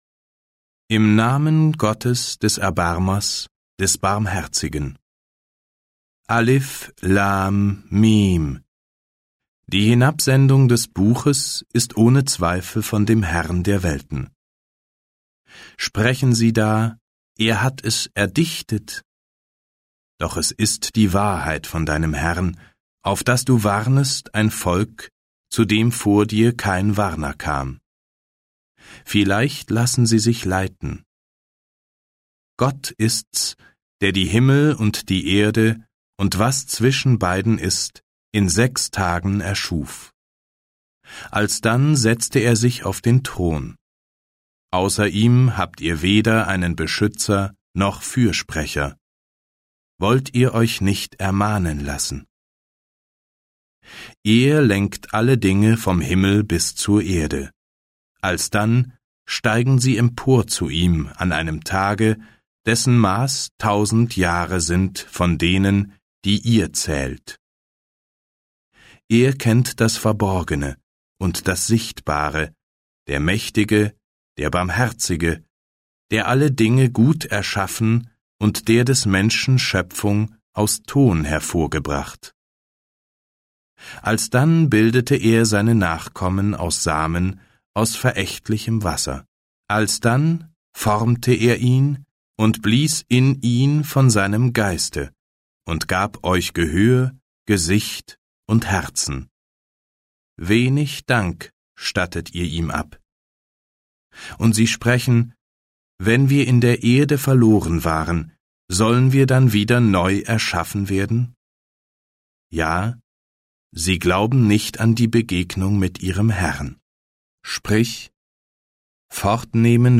Ungekürzte Lesung auf 2 mp3-CDs